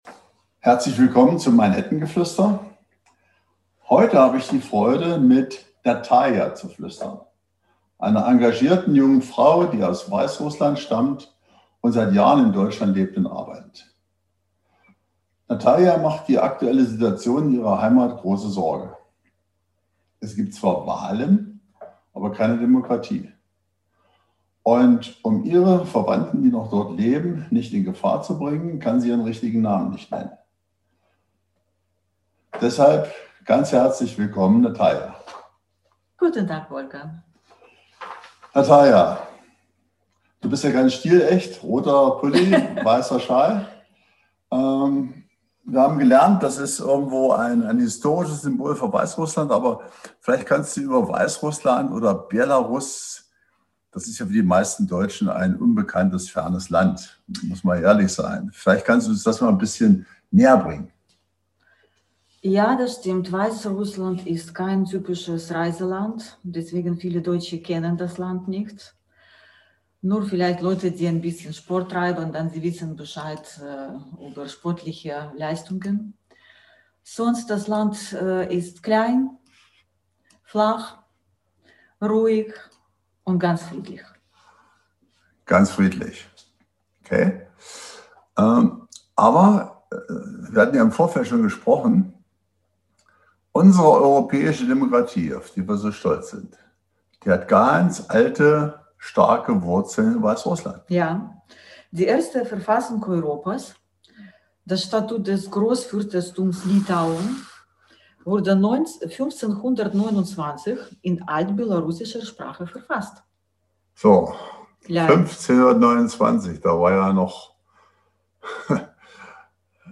Emotional bringt sie uns ihre scheinbar ferne Heimat Belarus näher und spricht offen über Ihre Gefühle seit die Diktatur in ihrer Heimat ihr brutales Gesicht zeigt.